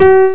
INTERACTIVE PIANO
the note should sound out.